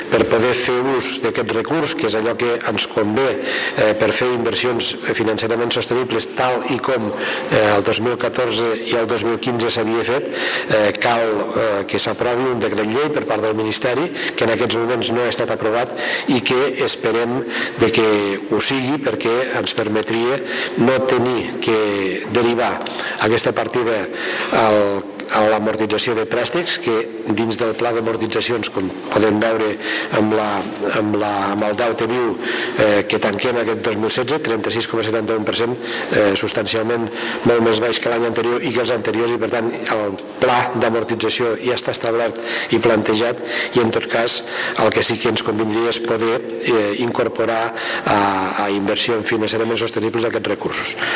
El president de la Diputació de Lleida, Joan Reñé, explica que per poder fer ús d’aquest recurs per fer inversions financerament sostenibles, tal i com s’ha fet els anteriors anys 2014 i 2015, cal que el govern de l’Estat espanyol aprovi un decret llei